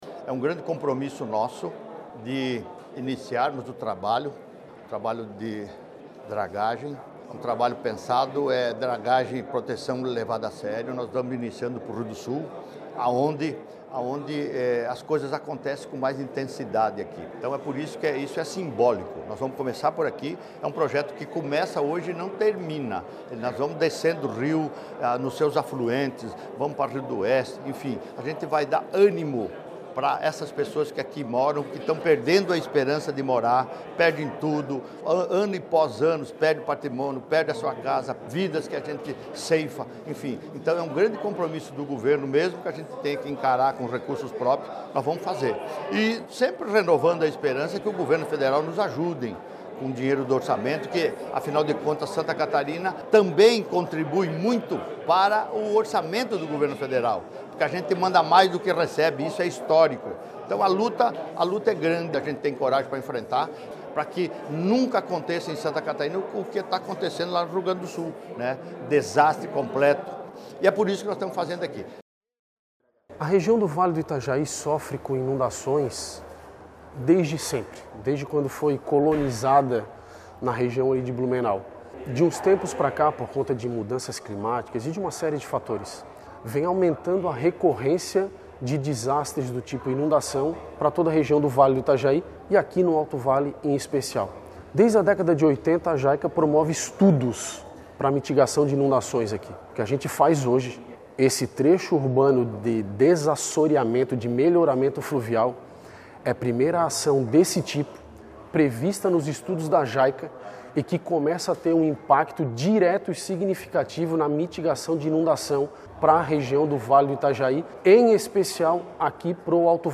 O governador Jorginho Mello, falou do compromisso de iniciar o trabalho e aliviar a dor e o sofrimento do pessoal do Alto Vale e região:
SECOM-Sonoras-Inicio-dragagem-do-Rio-Itajai-Acu-em-Rio-do-Sul-1.mp3